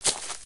sounds / material / human / step / grass02gr.ogg
grass02gr.ogg